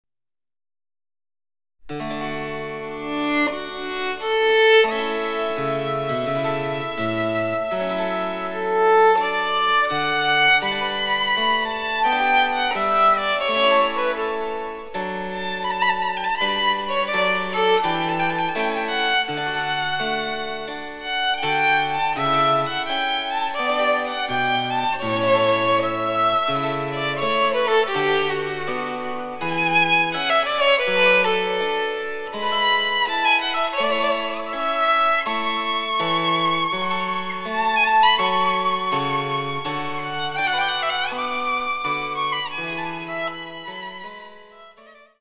★ヴァイオリン用の名曲をチェンバロ伴奏つきで演奏できる、「チェンバロ伴奏ＣＤつき楽譜」です。
(1)各楽章につきモダンピッチ(A=442Hz)の伴奏